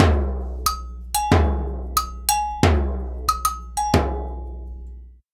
90 AGOGO01.wav